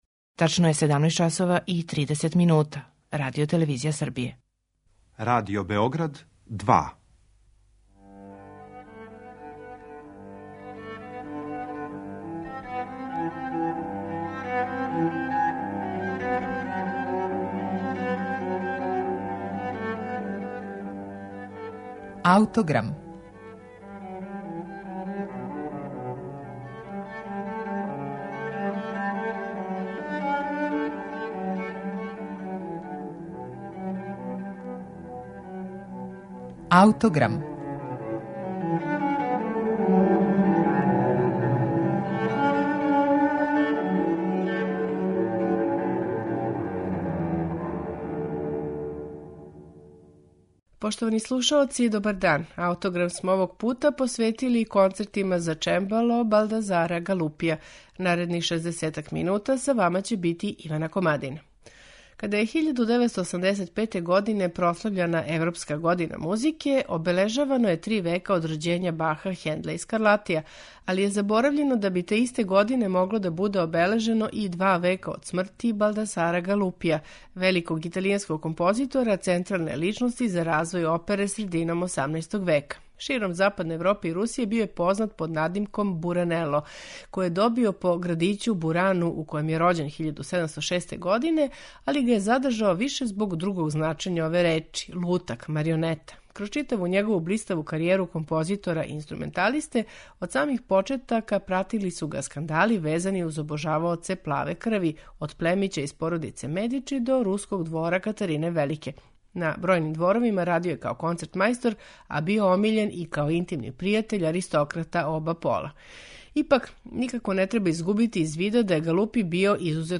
Шест концерата за чембало